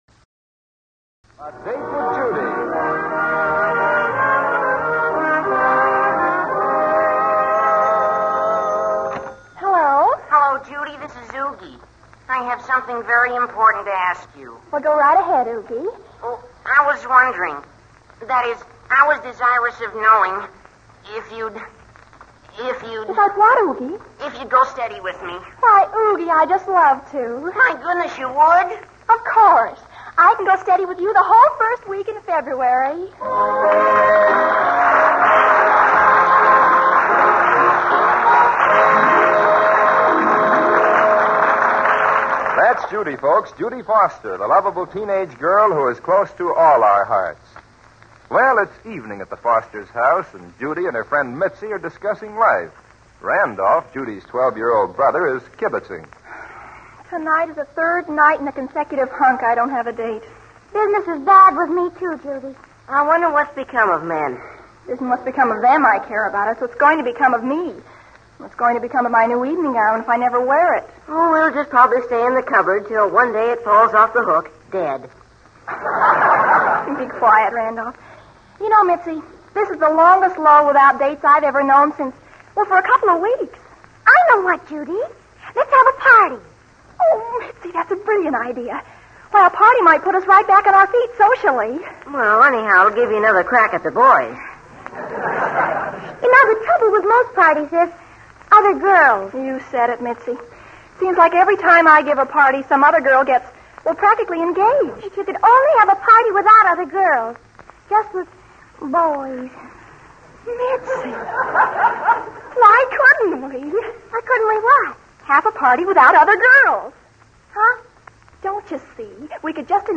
A Date with Judy Radio Program